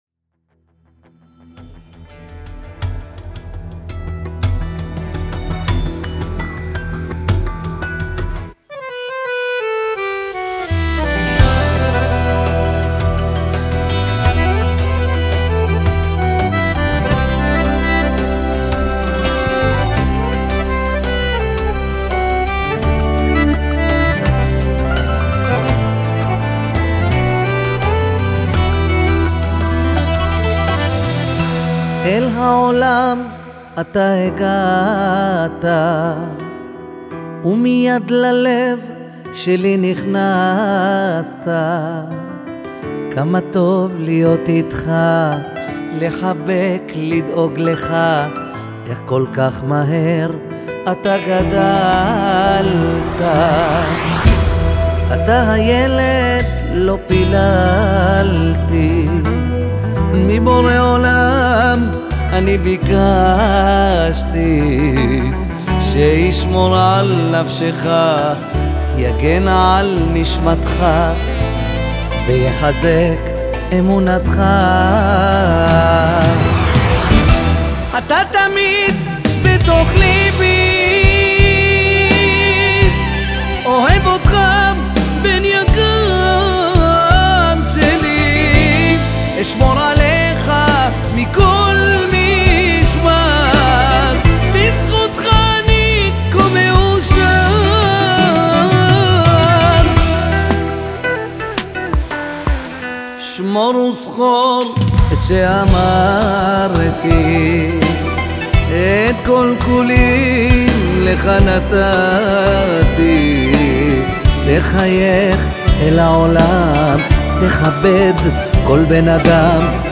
שירים חסידיים